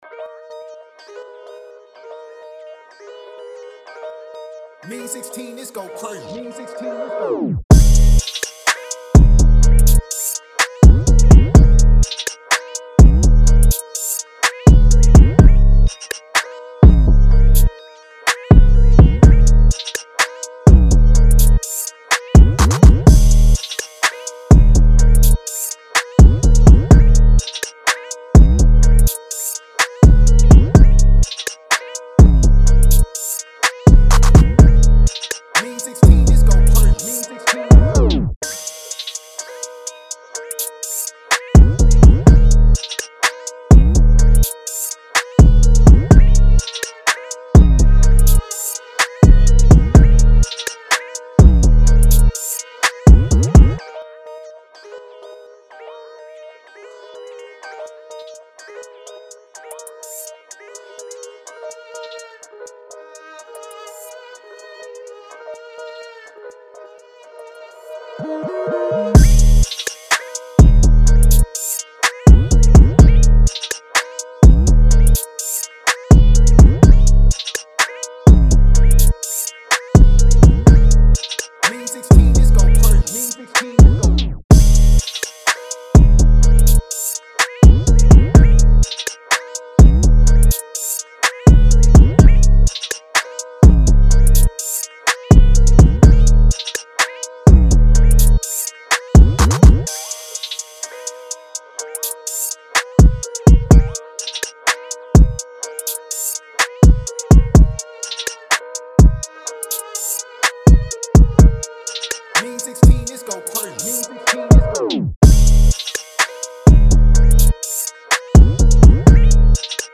DRILL
D#-Min 125-BPM